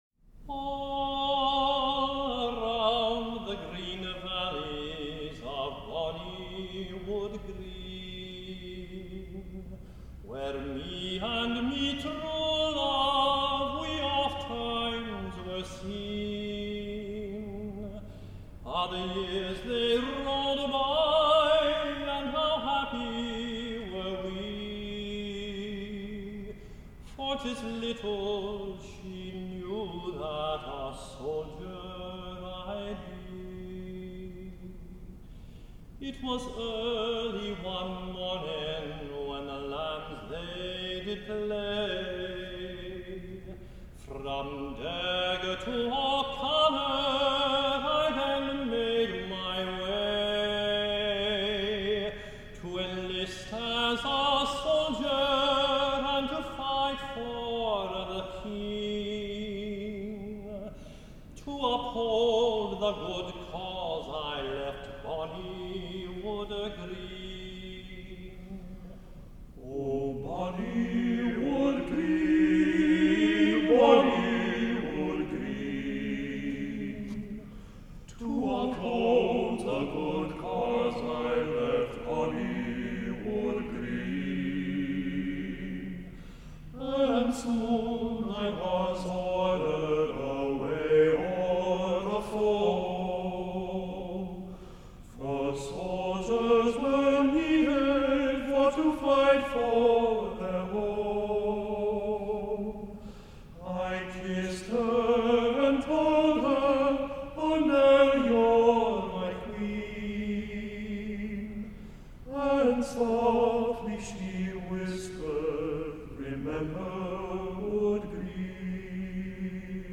Voicing: TBB